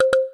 mixkit-click-error-1110-short.wav